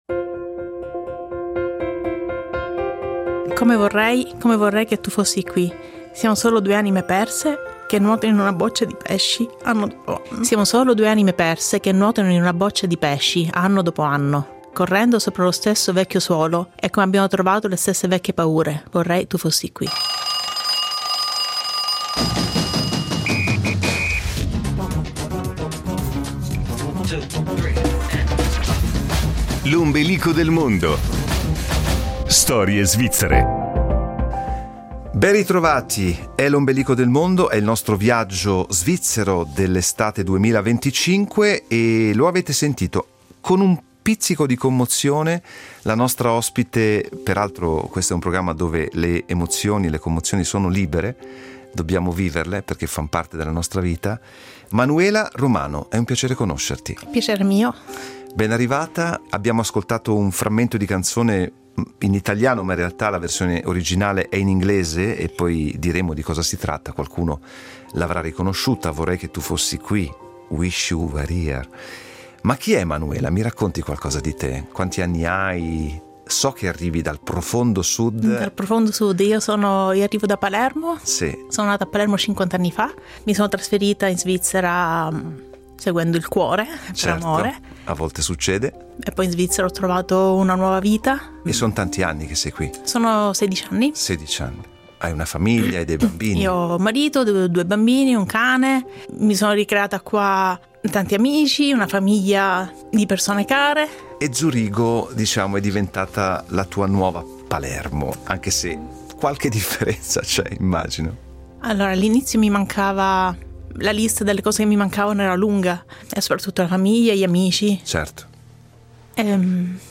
Musicalmente ha scelto un brano di grande intensità dei Pink Floyd.